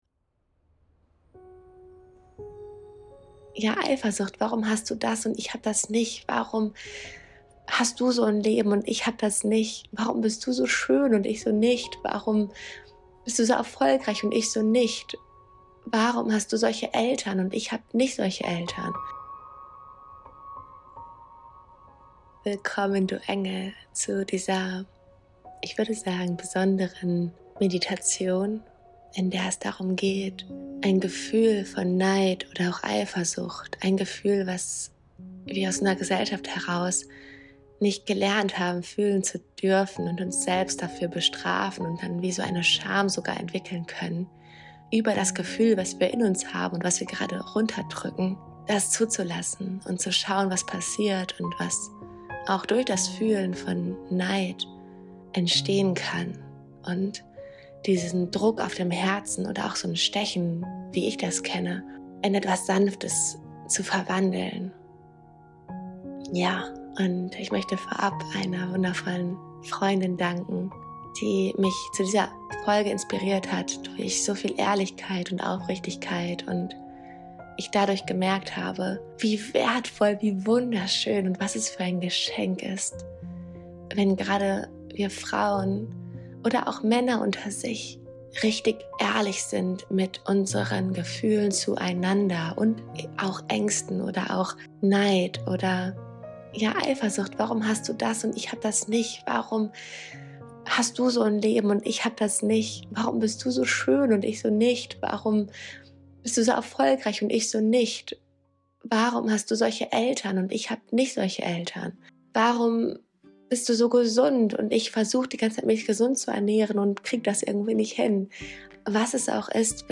In dieser geführten Meditation lade ich dich ein, alles da sein zu lassen.
Meditation_-_Neid-_Eifersucht_und_Scham_annehmen_und_den_Wandel_zulassen.mp3